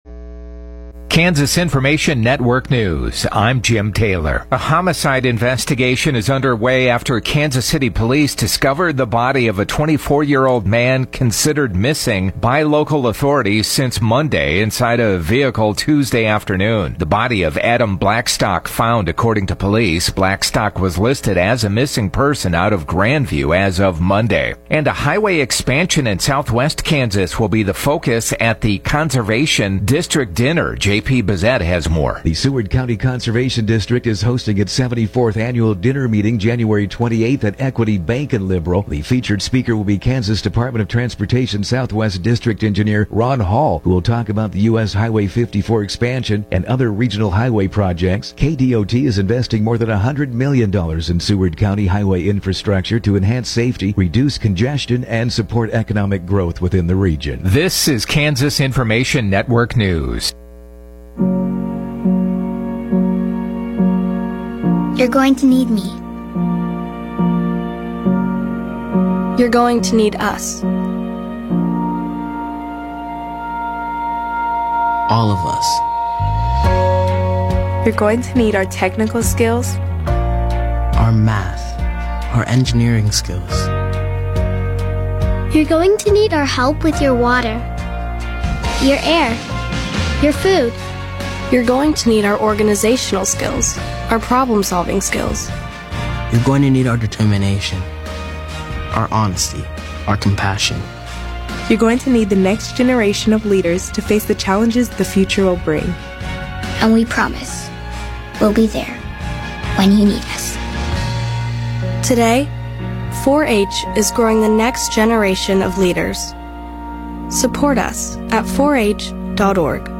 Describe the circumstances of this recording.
The KQNK News at Noon podcast gives you local, regional and state news as well as relevant information for your farm or home as well. Broadcasts are archived daily as originally broadcast on Classic Hits 106.7 KQNK-FM.